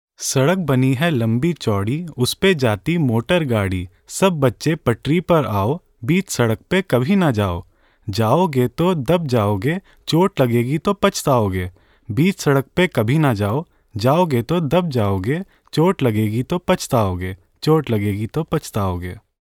Nursery Rhymes